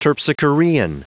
Prononciation du mot terpsichorean en anglais (fichier audio)